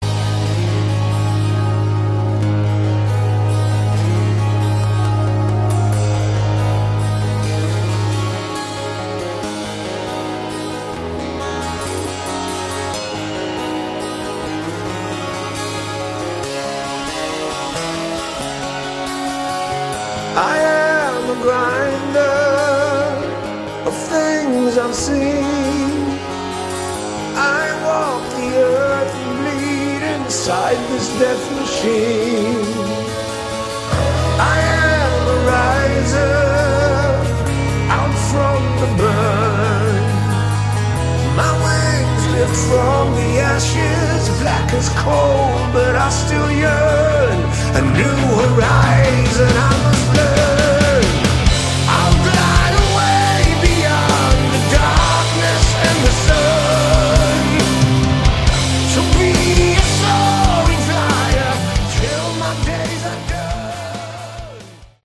Category: Hard Rock
vocals
bass
keyboards
guitars
drums